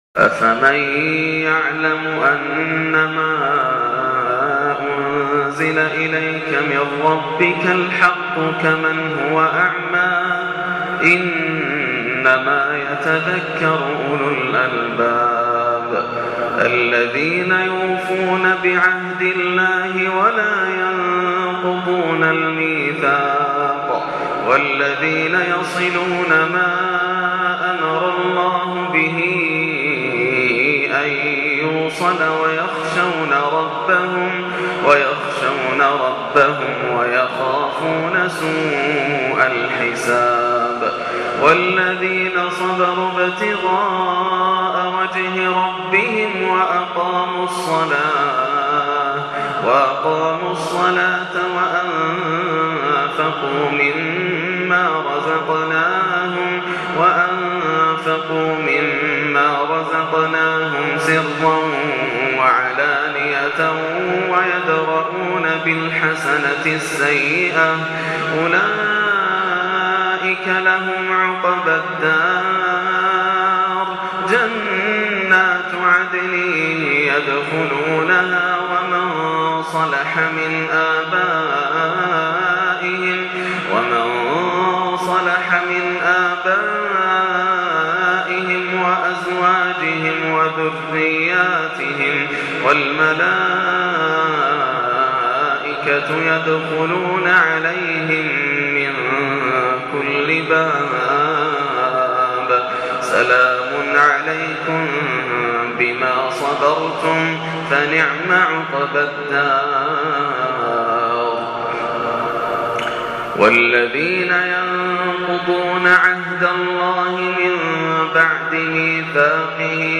تلاوة من سورة الرعد | فجر 4-6-1430 > عام 1430 > الفروض - تلاوات ياسر الدوسري